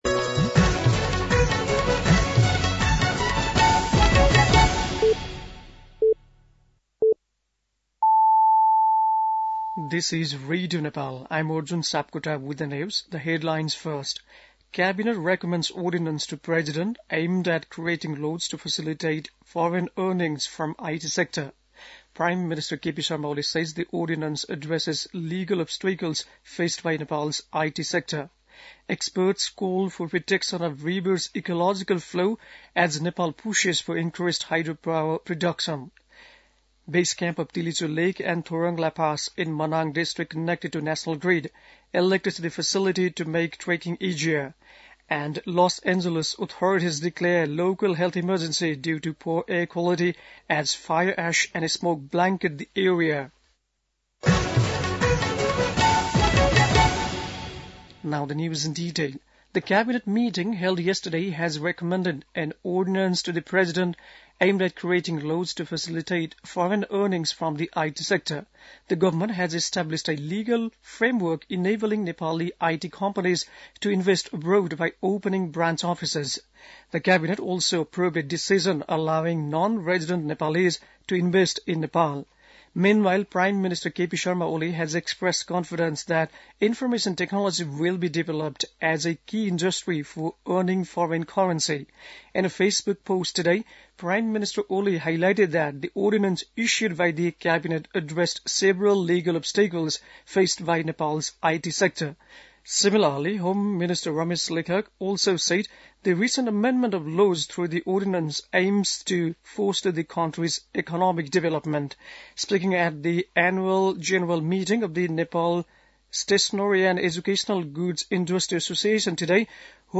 बेलुकी ८ बजेको अङ्ग्रेजी समाचार : २८ पुष , २०८१